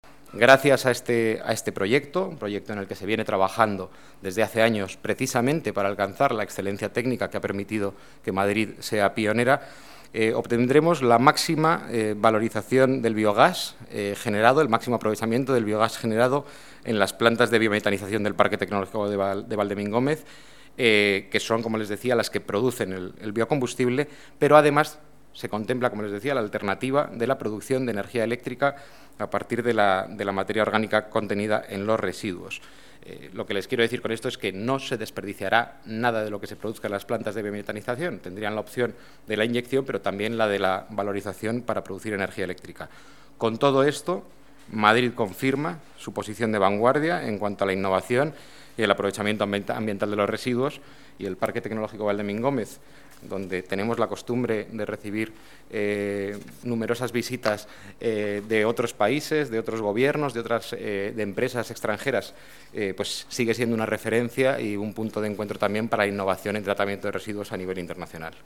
Nueva ventana:Declaraciones del delegado de Medio Ambiente y Movilidad, Diego Sanjuanbenito: planta biogás